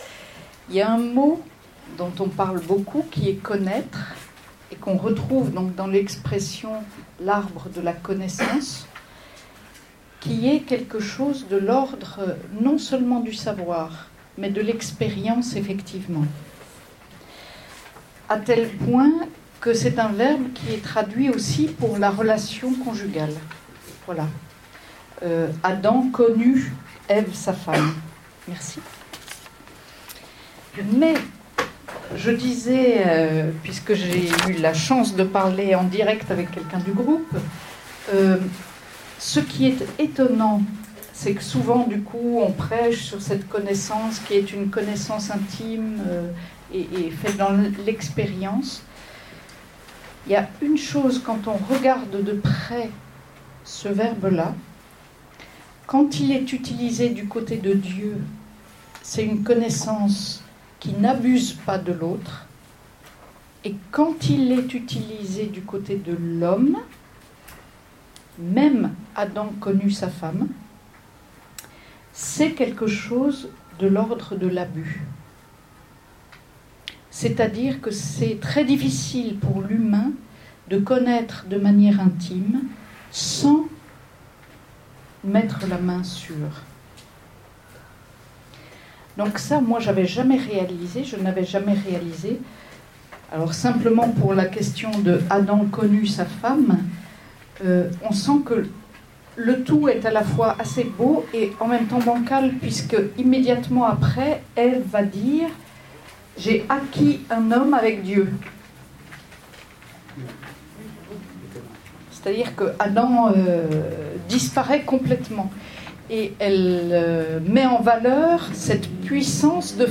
Week-end d’enseignement et de partage, les 19 et 20 juin 2016.